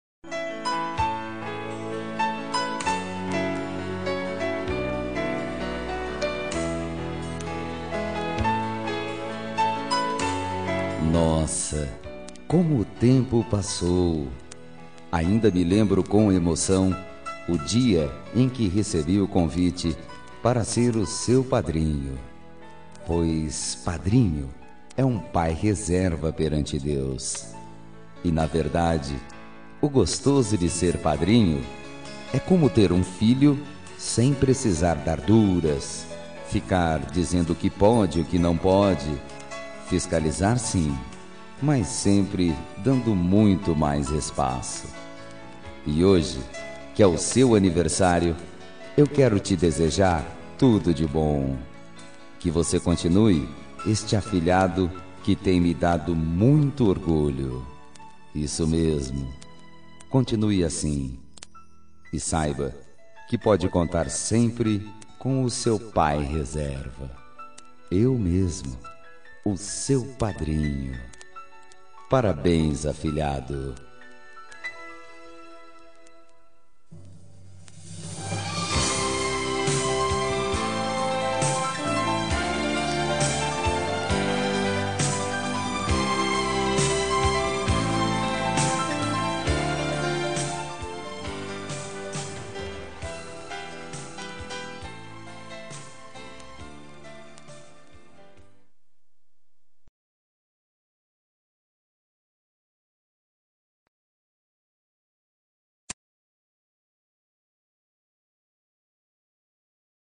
Aniversário de Afilhado – Voz Masculina – Cód: 2371